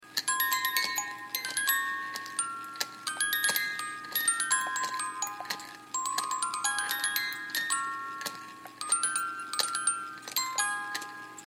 la bémol majeur